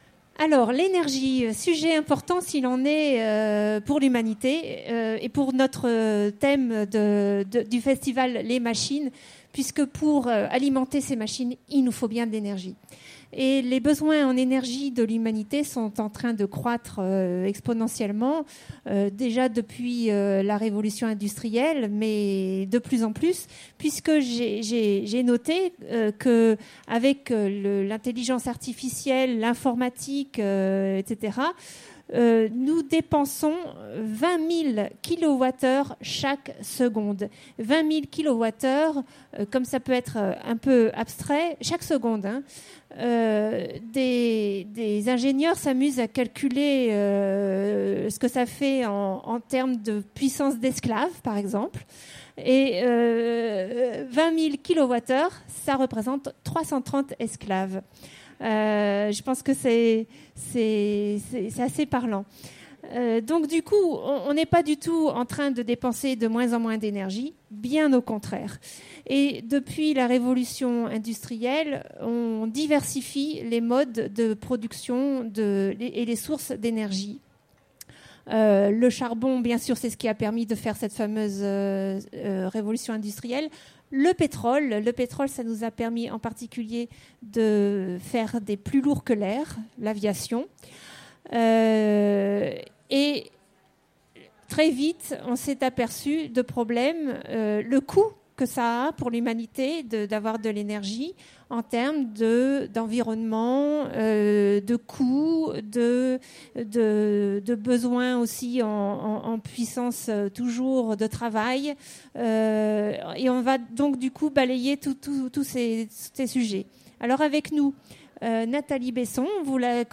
Utopiales 2016 : Conférence Machines à produire de l’énergie